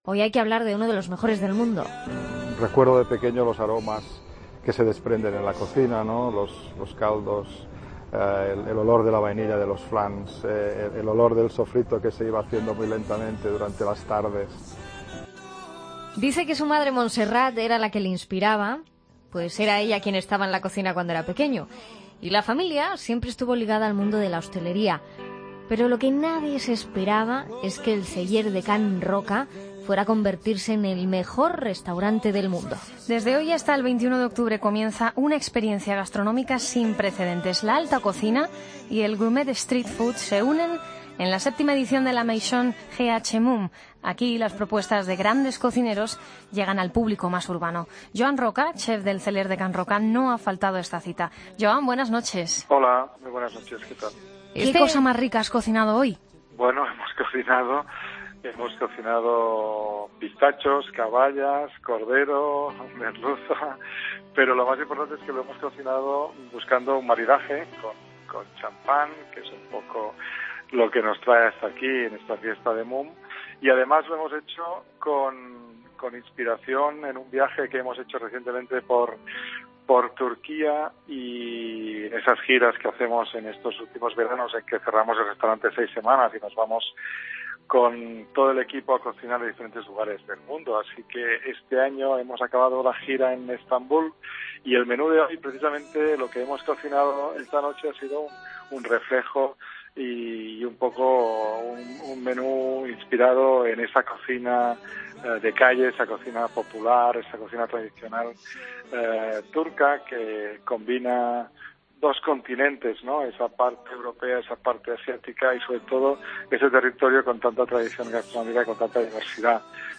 Entrevista a Joan Roca, chef de El Celler de Can Roca